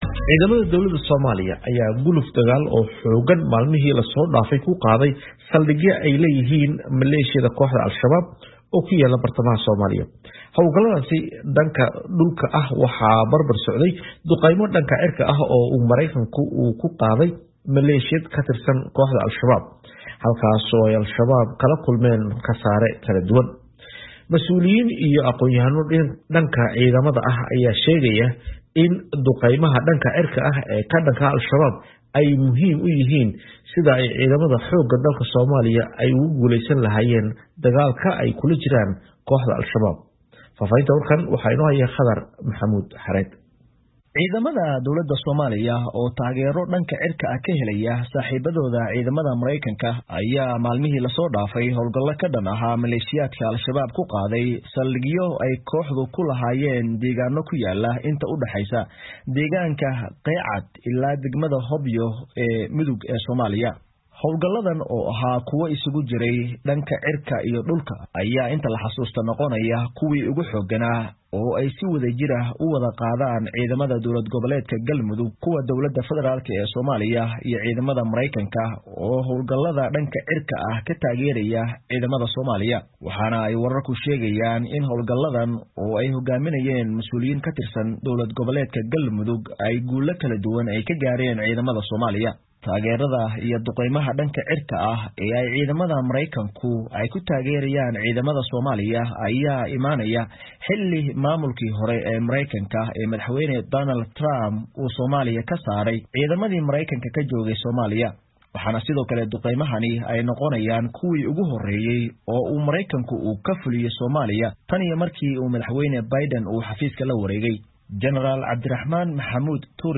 Warbixin: Ahmiyadda Duqeymaha Cirka ee Dagaalka ka Dhanka ah Al-Shabaab